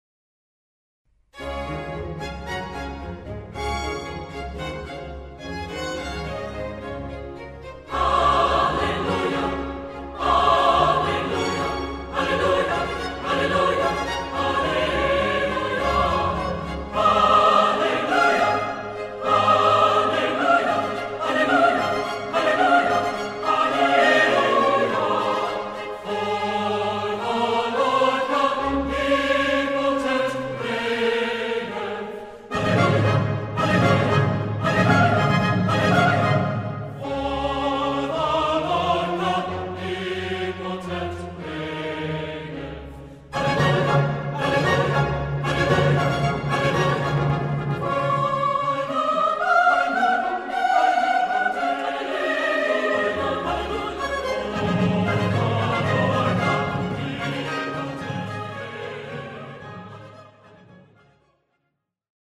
- Haendel "Hallelujah" para coro
Escuchar (sólo en caso de contar con 4 o más voces)